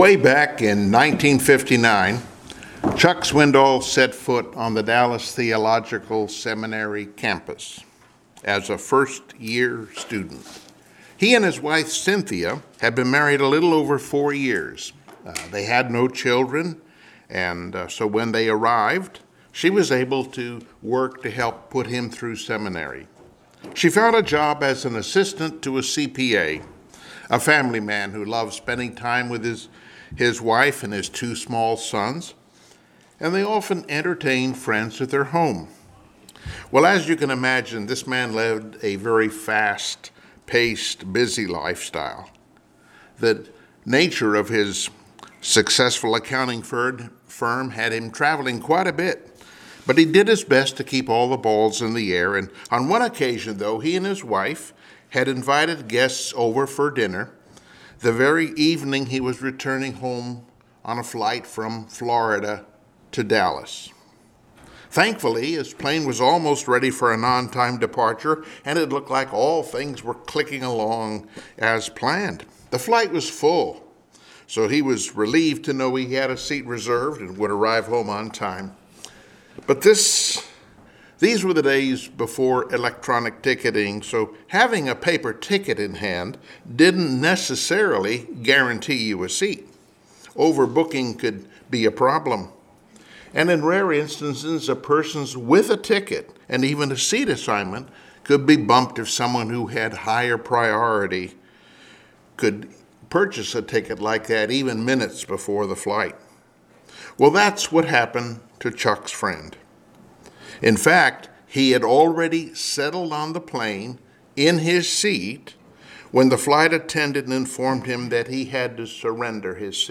Passage: Acts 16:1-10 Service Type: Sunday Morning Worship